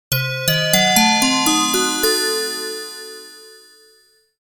Short Magical Transition Melody
Description: Short magical transition melody. Enchanted transition chime. Add a mystical sparkle to your videos, games, apps, and infographics with this brief, magical sound effect.
Genres: Sound Logo
Short-magical-transition-melody.mp3